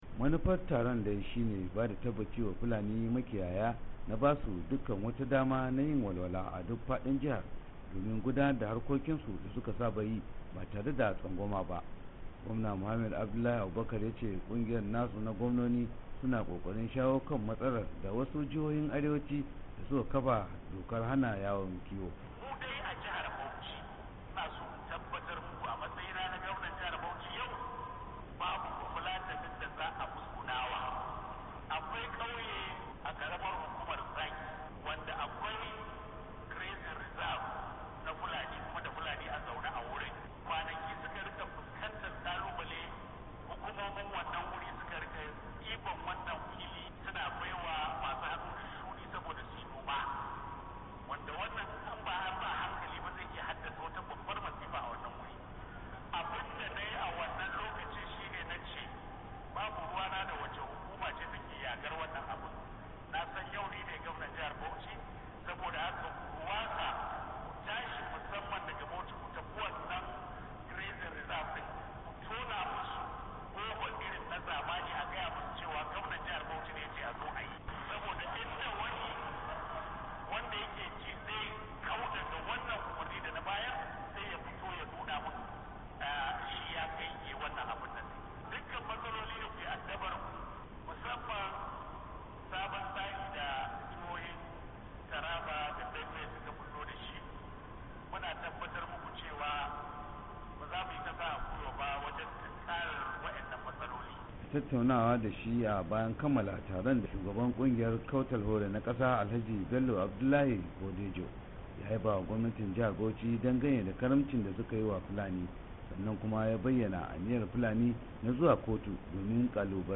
WASHINGTON D.C. —